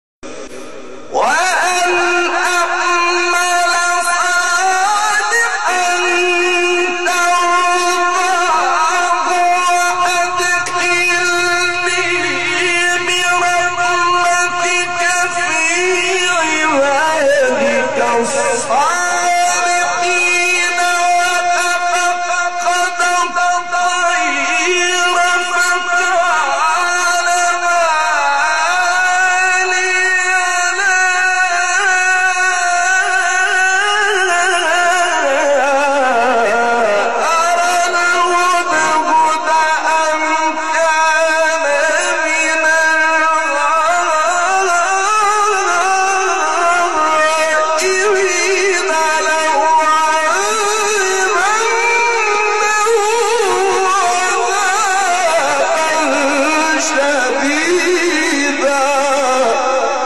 فرازهای صوتی از قاریان به‌نام مصری
فرازی از محمد عمران در مقام رست/ سوره حریم